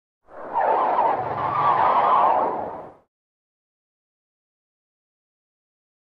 Eerie Low Frequency Synth Gusts.